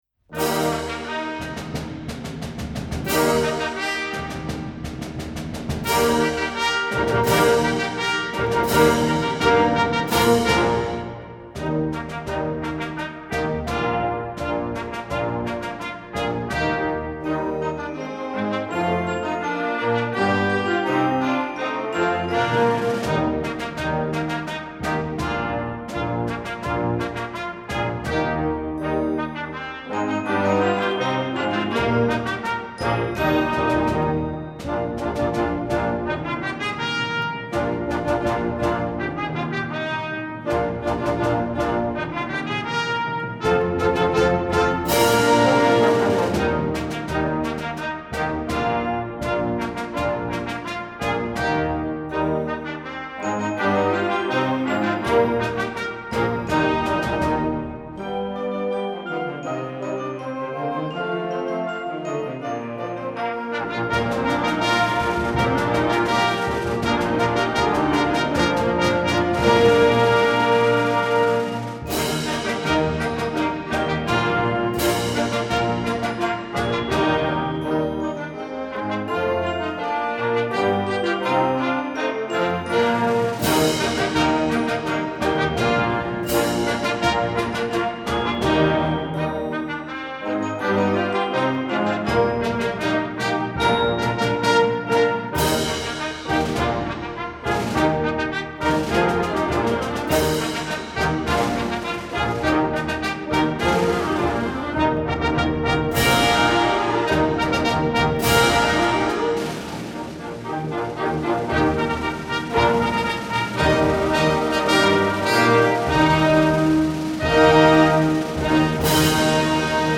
Voicing: Trumpet w/ Band